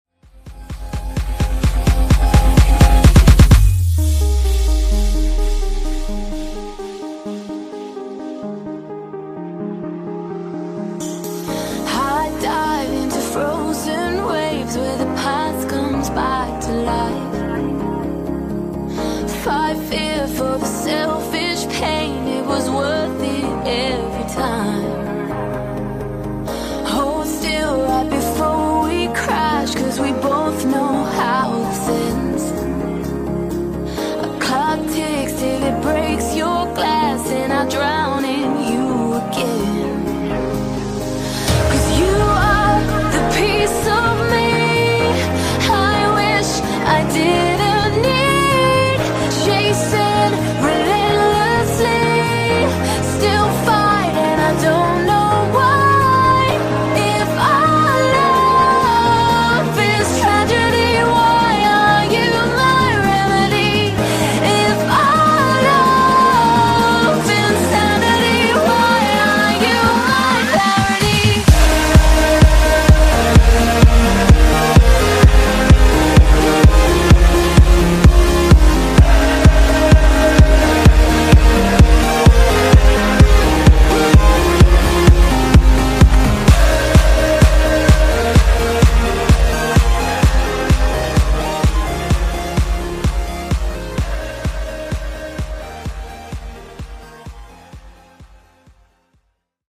Genres: EDM , RE-DRUM
Clean BPM: 128 Time